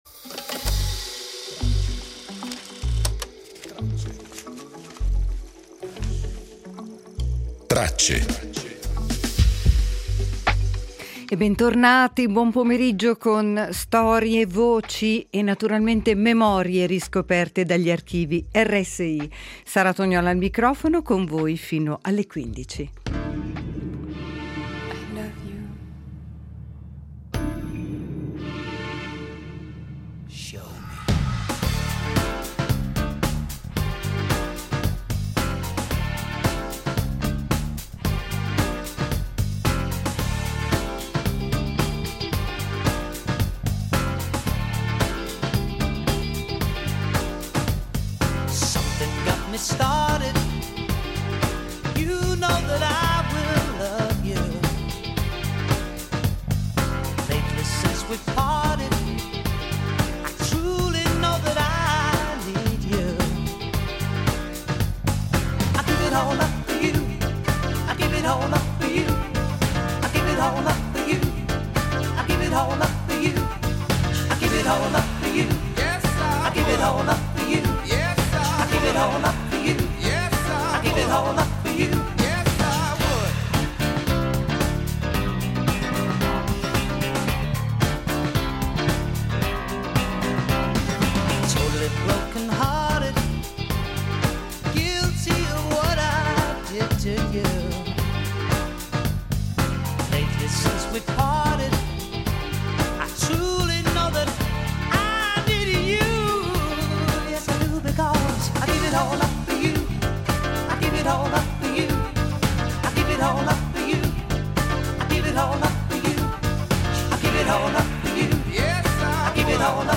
Nella puntata di oggi riportiamo alla luce alcuni fili preziosi del nostro archivio: la voce giovane di Umberto Eco nel 1973, a dieci anni dalla sua scomparsa; la delicatezza intensa di Harper Lee e del suo Il buio oltre la siepe , tra romanzo e cinema; la forza lieve e malinconica di Massimo Troisi , nel suo modo unico di raccontare Napoli e se stesso; e infine la storia vera di Fiordaliso , una voce che nasce nei piano‑bar e diventa un segno inconfondibile della musica italiana.